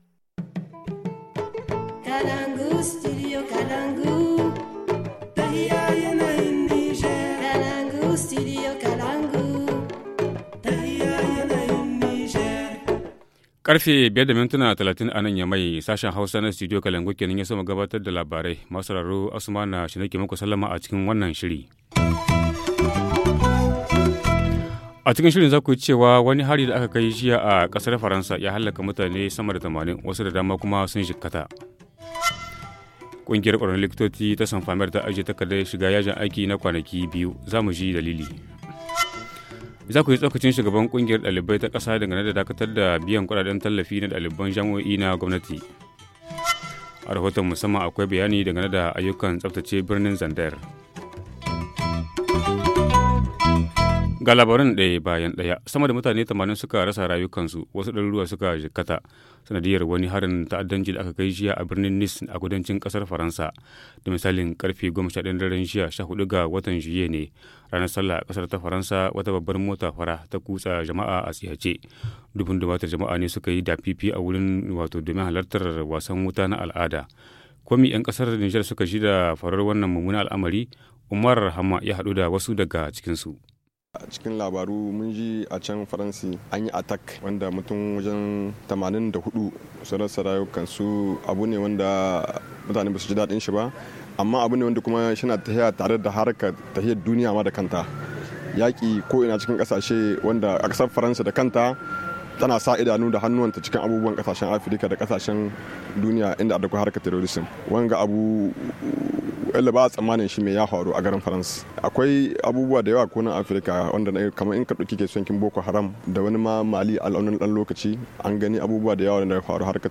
L’opinion de quelques citoyens et l’analyse d’un spécialiste dans un instant
Journal en français